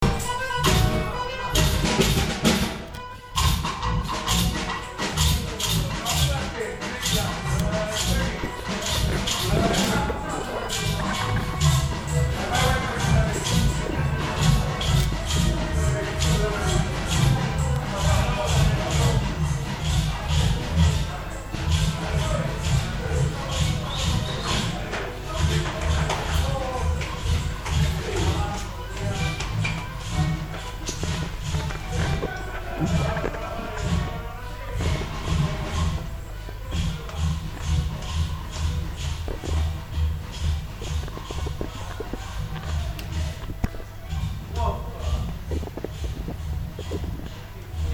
Dopo aver ringraziato anche chi, in rigorosa segretezza, ha fornito il titolo di questo pezzo e del successivo in tema, si comincia con una marcetta che essendo più che edita, sicuramente non ha centrata la vittoria.
Piazza Municipio Con 28 Persone, del 1 gen 2022, ore 00.02